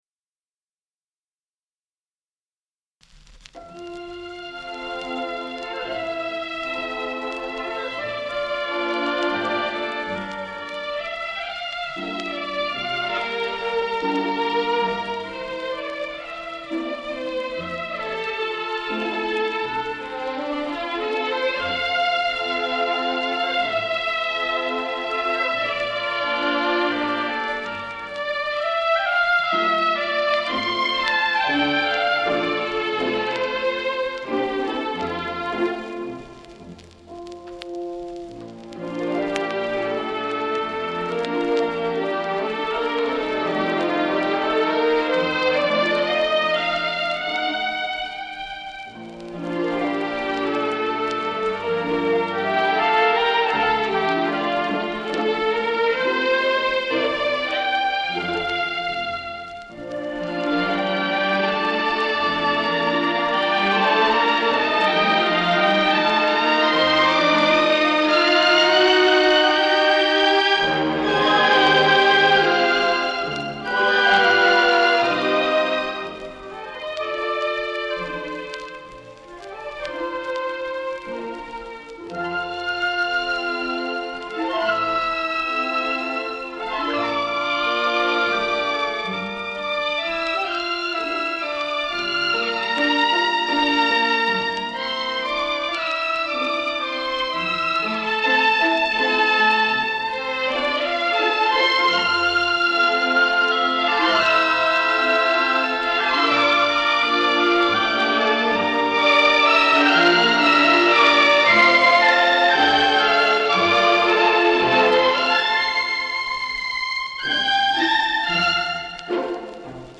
2. Ideal dynamic range plus clarity and brilliance.